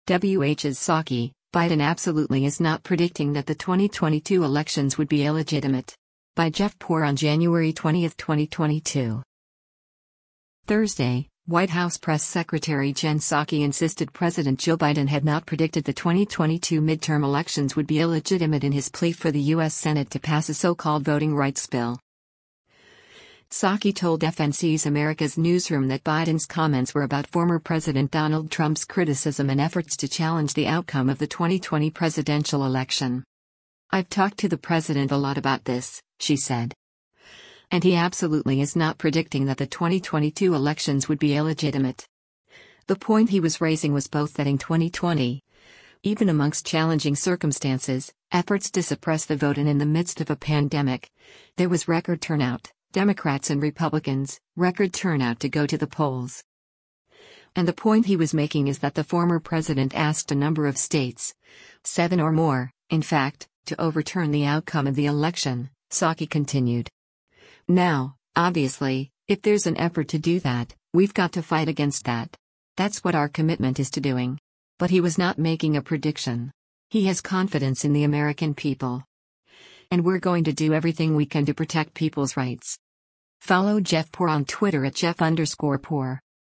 Psaki told FNC’s “America’s Newsroom” that Biden’s comments were about former President Donald Trump’s criticism and efforts to challenge the outcome of the 2020 presidential election.